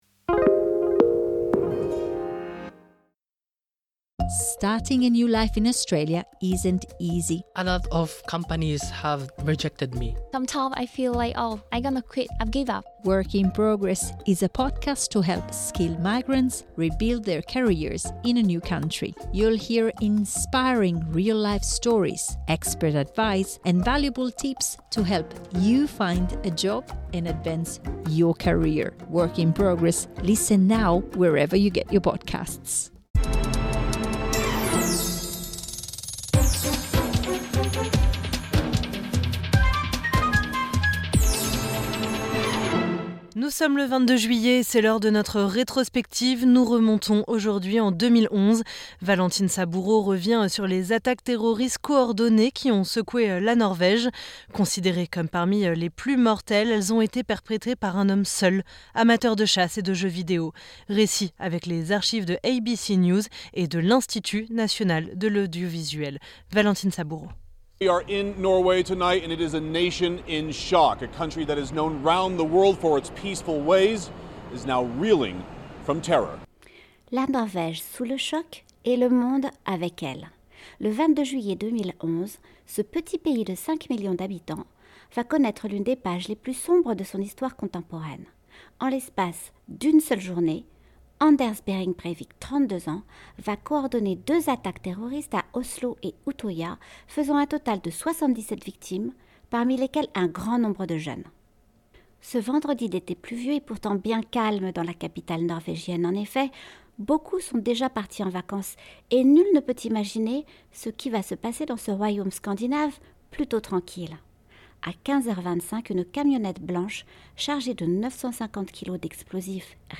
Elles ont été perpétrées par un homme seul, amateur de chasse et de jeux vidéo. Récit avec les archives de ABC news et de l'Institut National de l'audiovisuel.